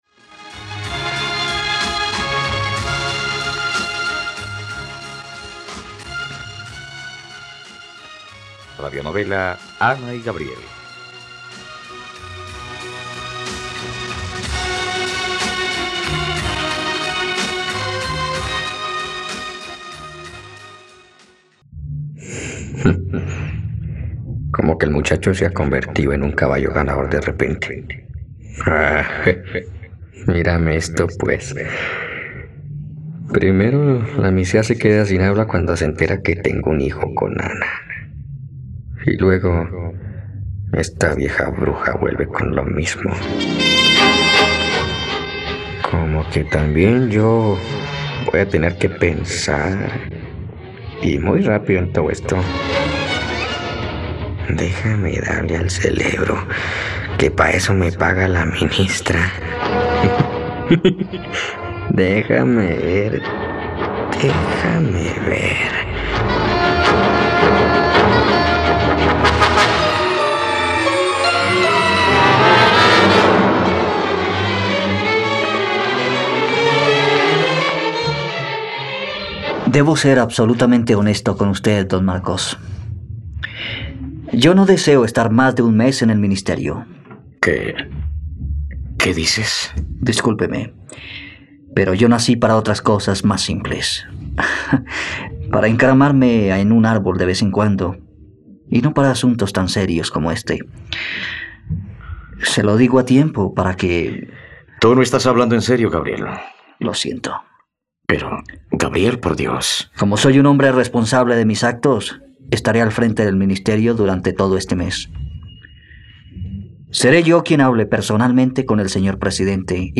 ..Radionovela. Escucha ahora el capítulo 82 de la historia de amor de Ana y Gabriel en la plataforma de streaming de los colombianos: RTVCPlay.